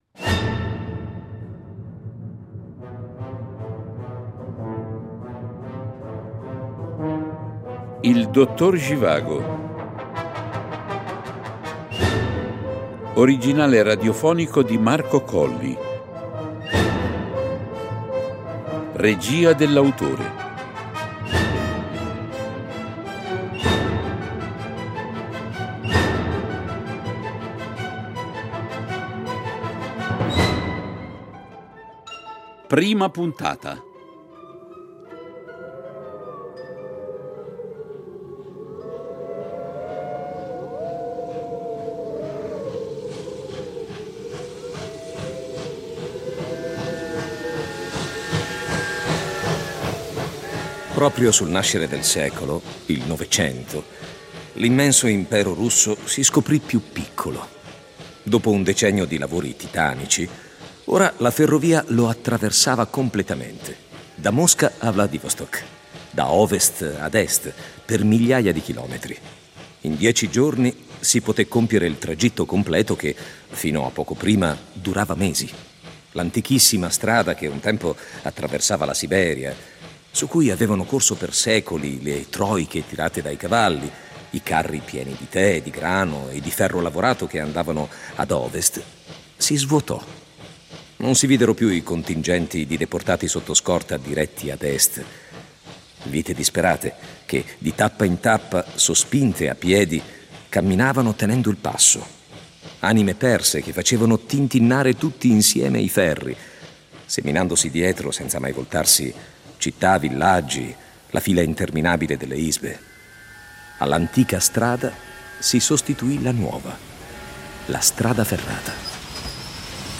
Originale radiofonico